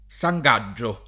San Gaggio [ S a j g#JJ o ] top. (a Firenze)